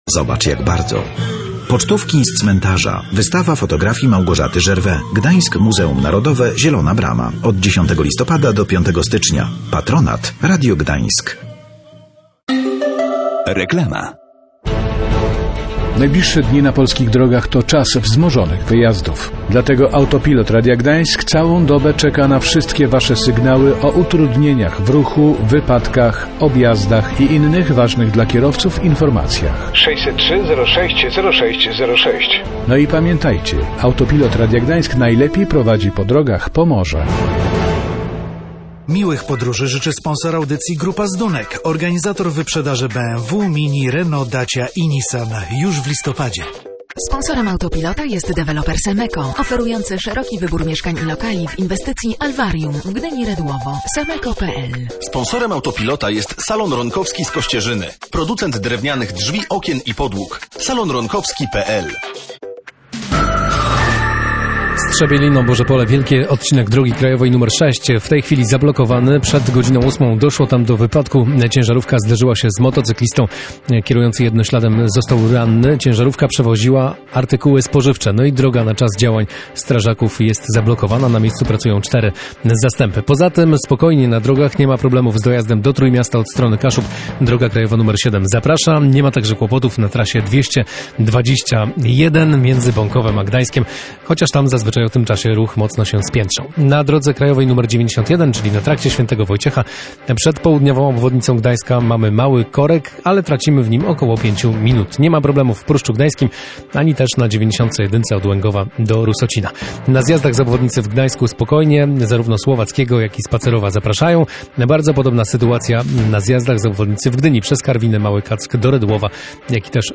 Gościem Dnia Radia Gdańsk o 8:30 był wojewoda pomorski Dariusz Drelich.